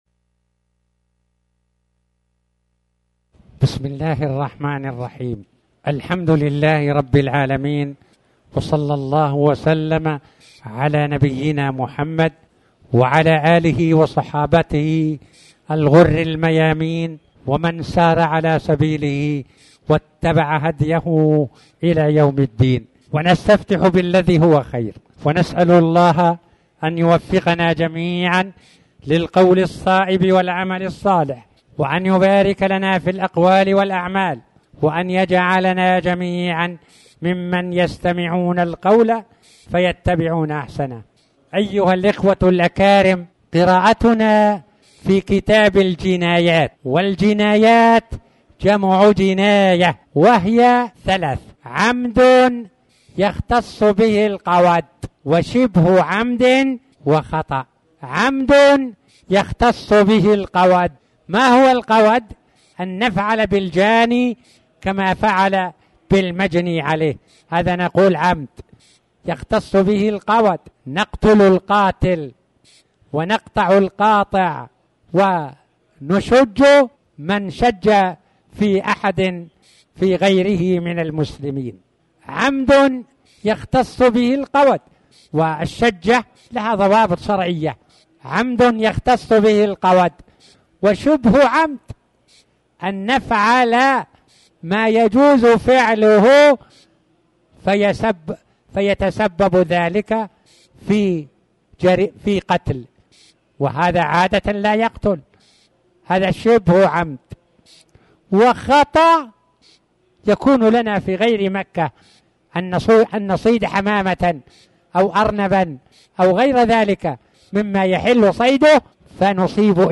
تاريخ النشر ١٠ رجب ١٤٣٩ هـ المكان: المسجد الحرام الشيخ